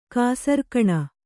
♪ kāsarkaṇa